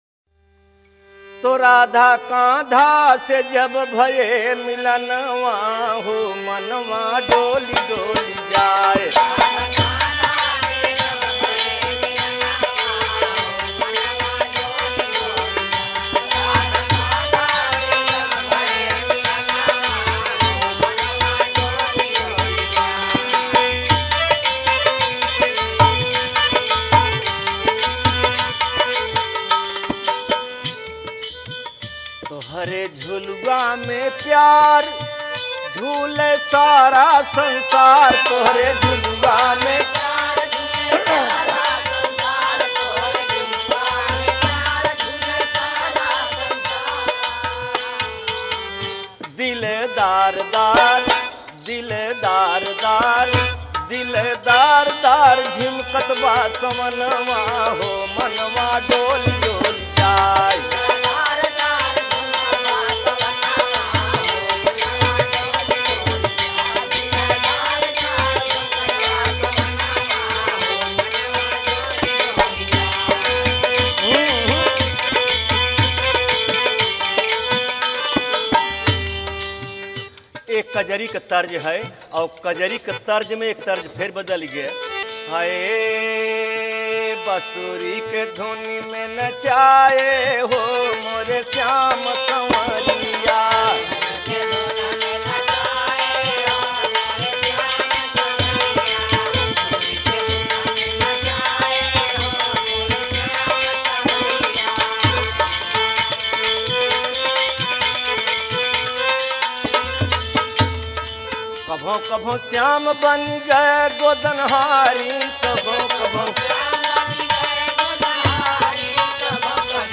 Rajasthani Songs